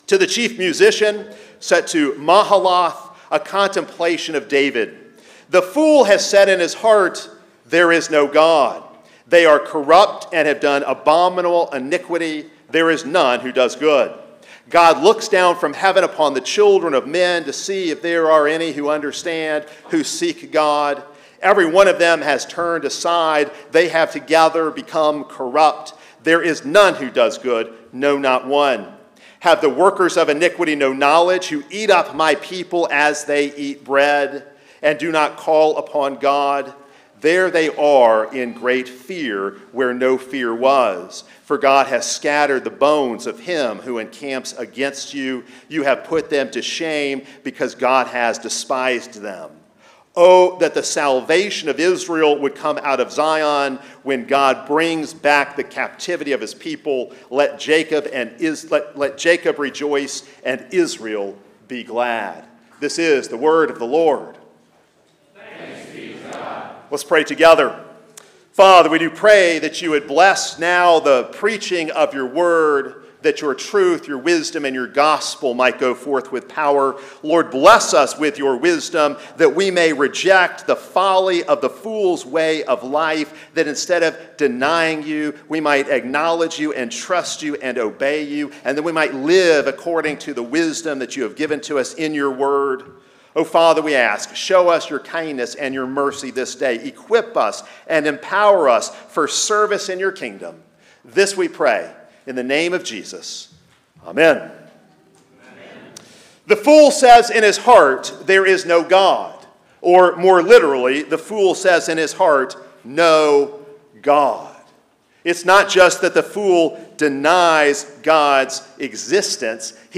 # Religion